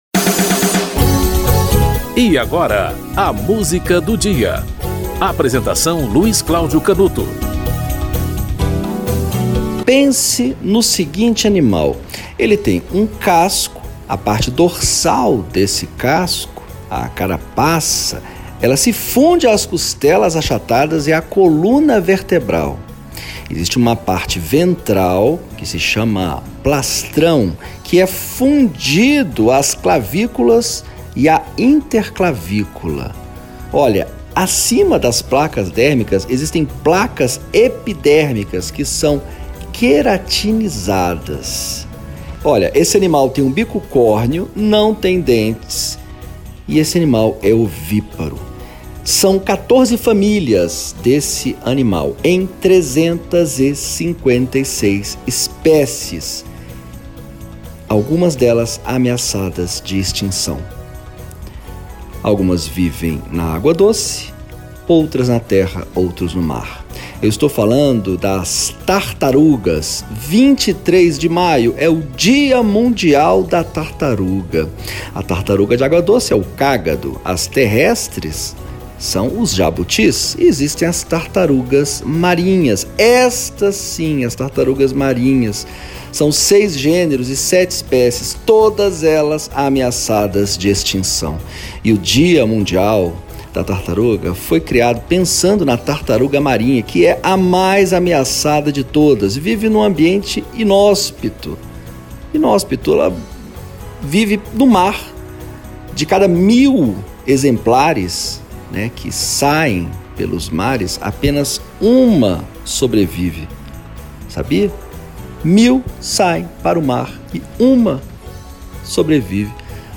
Adriana Calcanhoto - Canção da Falsa Tartaruga (Cid Campos, Lewis Carroll e Augusto de Campos)
O programa apresenta, diariamente, uma música para "ilustrar" um fato histórico ou curioso que ocorreu naquele dia ao longo da História.